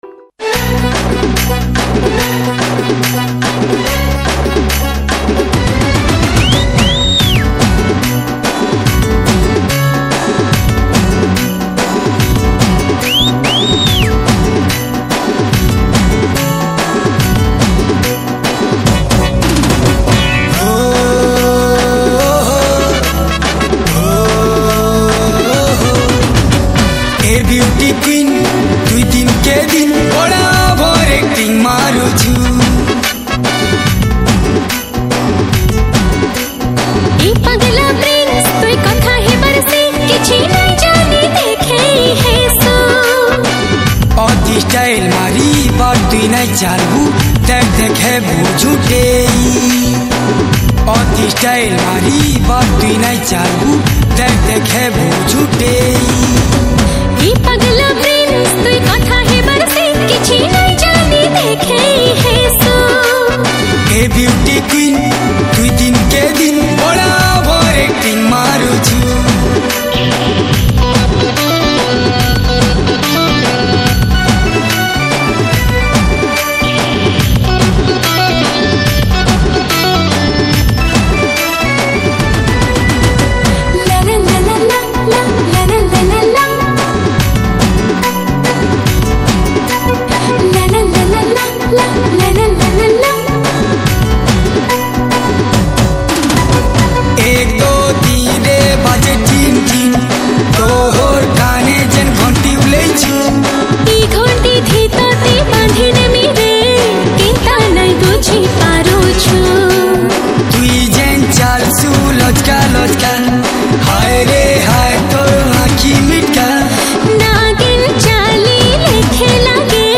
Sambalpuri